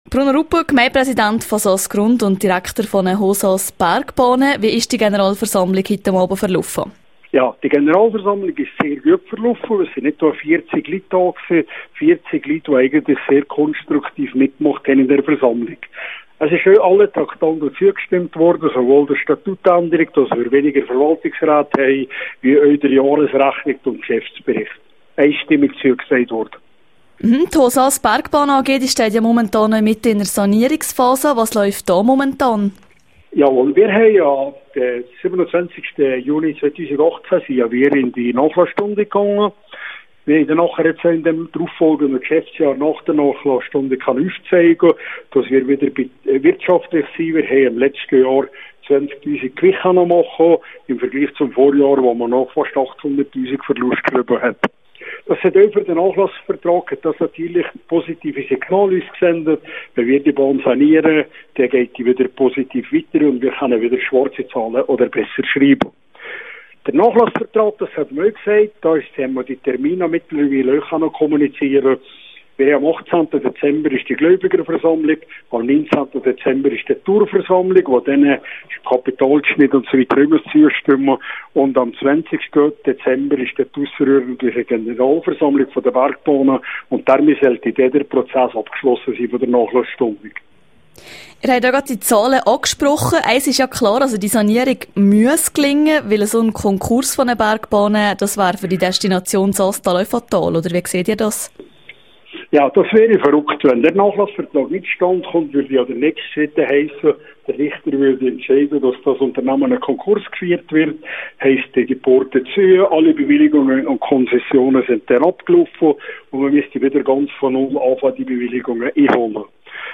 Im Interview mit rro erwähnte Bruno Ruppen ausserdem, entgegen einigen Medienberichten, dass die Hohsaas Bergbahnen AG weiterhin Teil des Oberwalliser Skipasses seien./bn Generalversammlung der Hohsaas Bergbahnen AG: Interview mit Bruno Ruppen, Direktor und Gemeindepräsi (Quelle: rro)